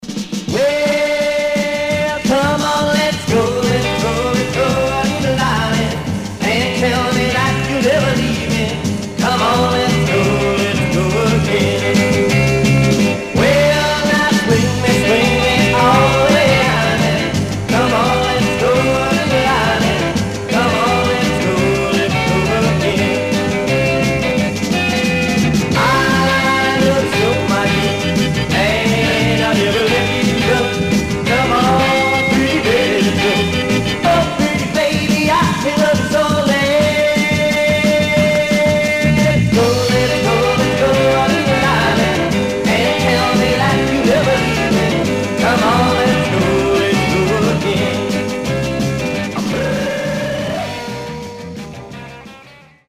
Surface noise/wear
Mono
Garage, 60's Punk